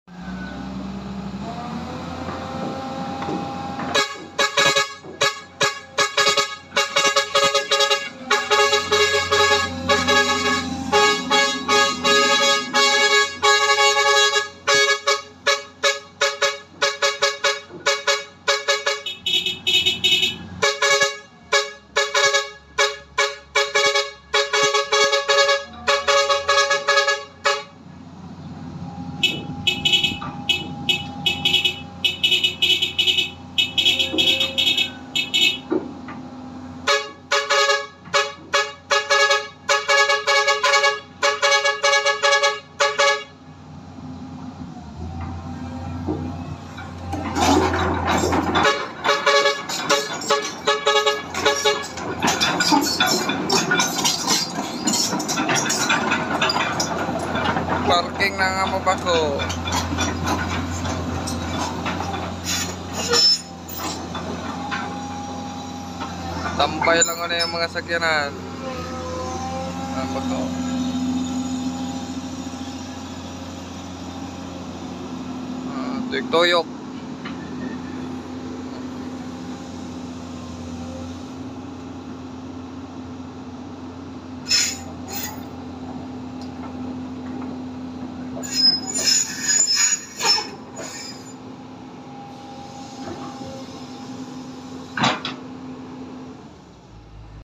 Rcgs backhoe 4ft sound check sound effects free download